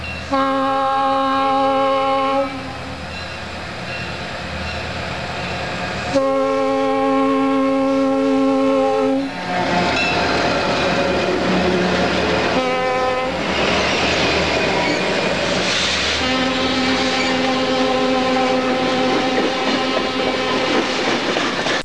Factory tuning for the M3 was: A major triad (C#,E,A).
Over time as a result of use, C#,D#,A, C#,E,A#, and C#,D#,A# dischords were common.
When bad things happen to good horns:
Otherwise, the horns soon went out of tune.